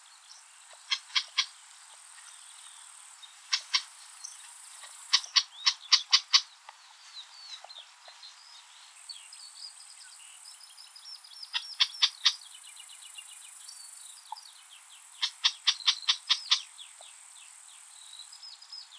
Lanius collurio - Red-backed shrike - Averla piccola
E 23°44' - ALTITUDE: +155 m. - VOCALIZATION TYPE: contact calls.
- COMMENT: This is a typical call of this species, similar to calls of other species of the genus Lanius.
Background: Skylark song.